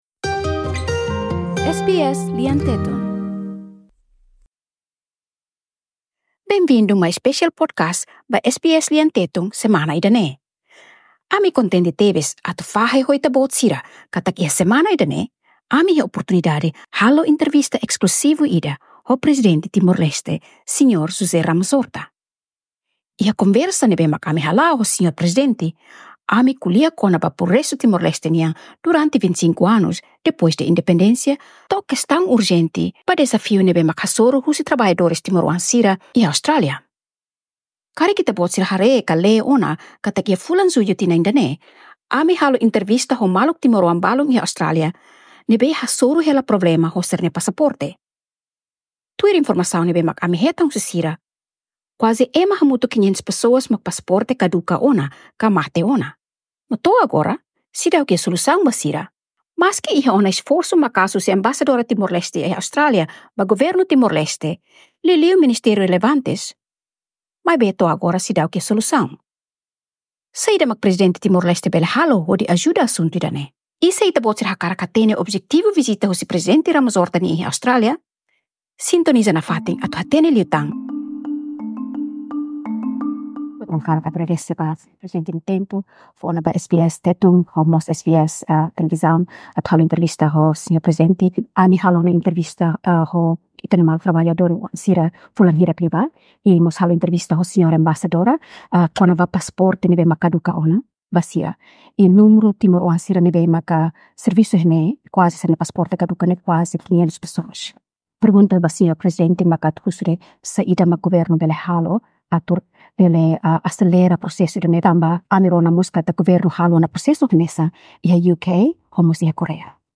Iha dia 6 de Outubro, SBS Tetum iha oportunidade hodi dada-lia ho Prezidente Timor-Leste, Sr. Jose Ramos-Horta iha fatin ne'ebe nia hela iha Sydney. Iha oportunidade ne'e ami koaloa kona-ba problema ne'ebe maluk Timor-oan traballadór sira hasoru, nune'e mos ho progresu ne'ebe Timor-Leste atinji ona durante 25 anos depois de vota ba Independensia.
Prezidente Timor-Leste, Jose Ramos Horta, durante intervista ho SBS Tetum